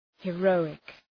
heroic.mp3